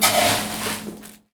R - Foley 71.wav